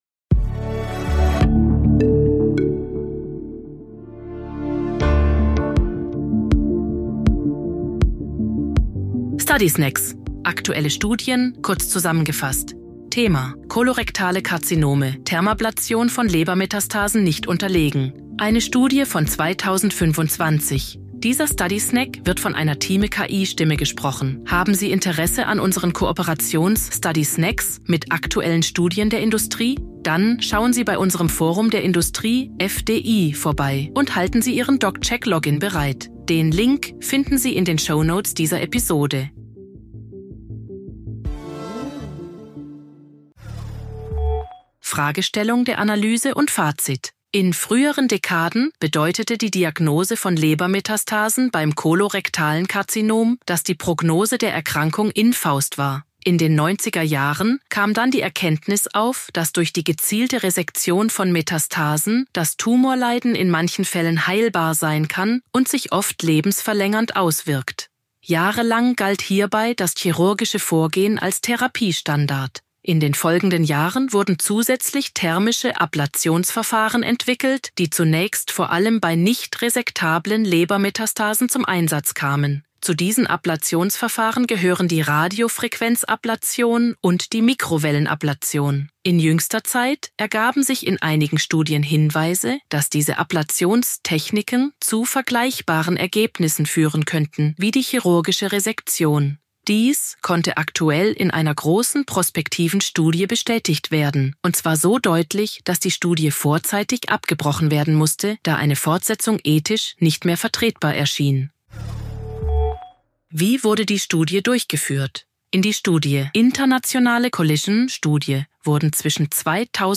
Übersetzungstechnologie gesprochene Texte enthalten